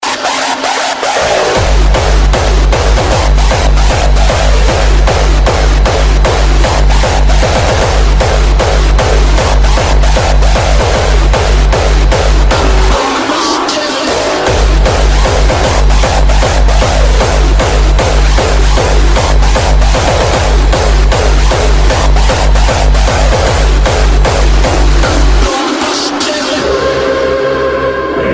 Electronica